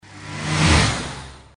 OvertakingSound_2.mp3